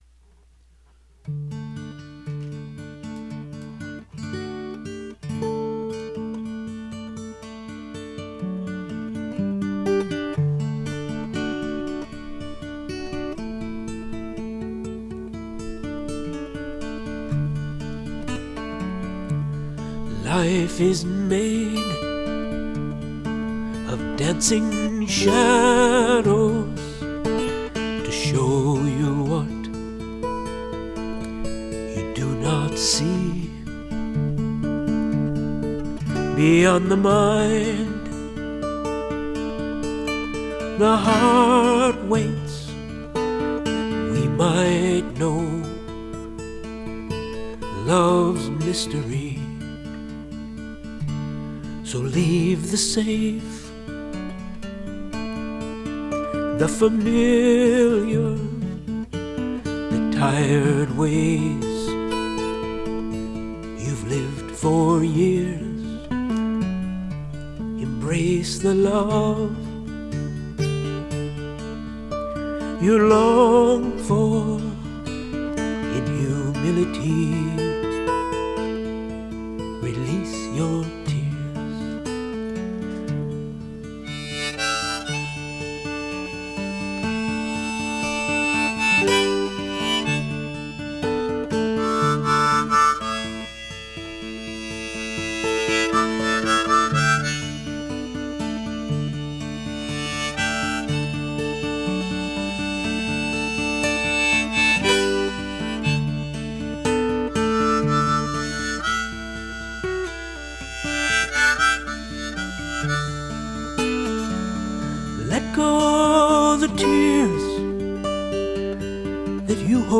Guitar, Vocals, Harmonica